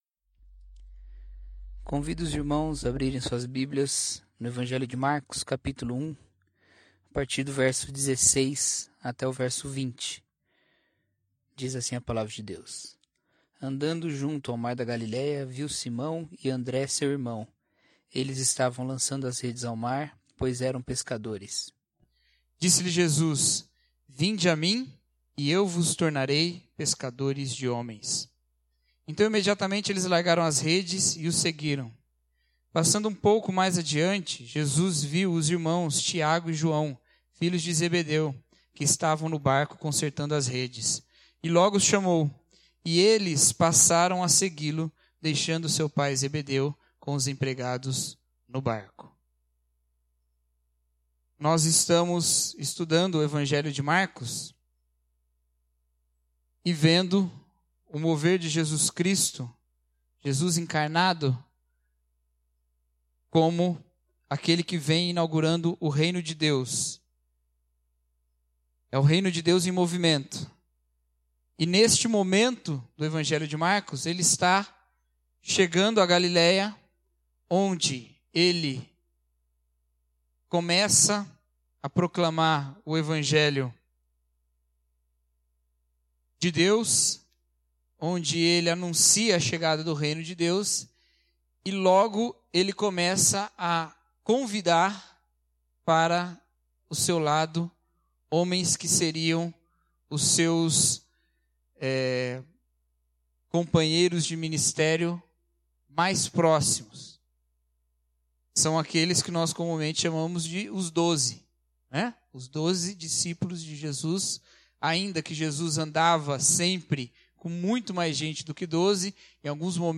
O Reino em Movimento Mensagem